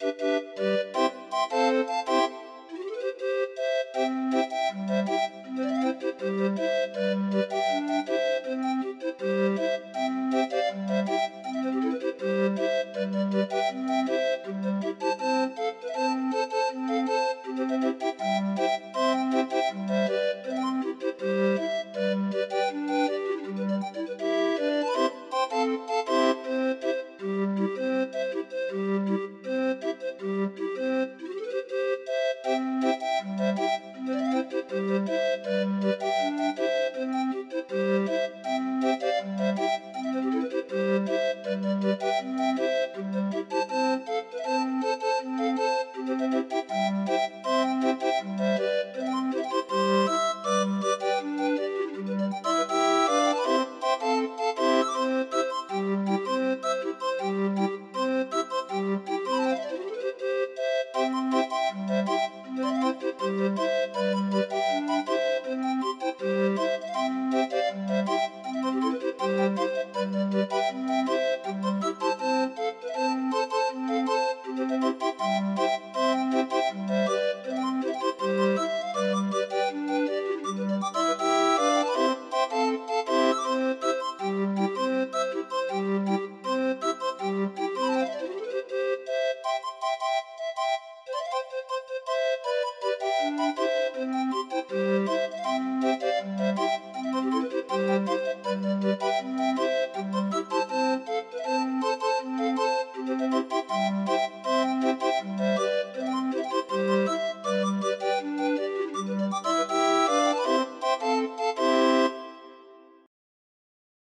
Demo of 25 note MIDI file